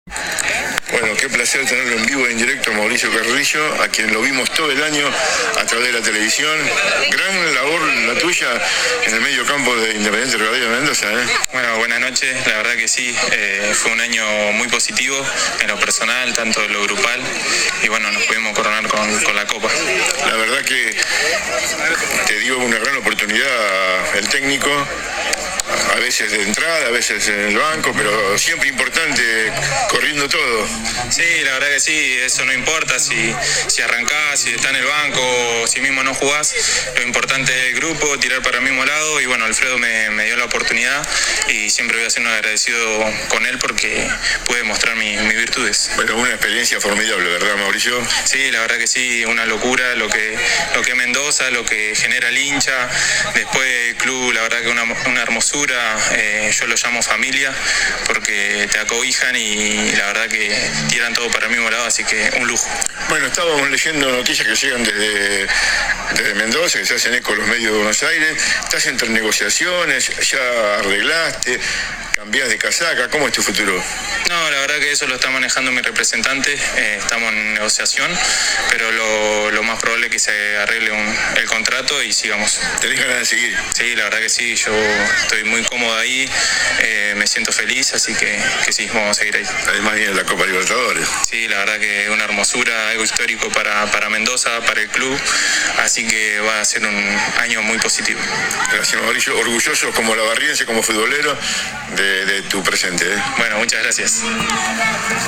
En un alto del evento dialogó con «Emblema».
AUDIO DE LA ENTREVISTA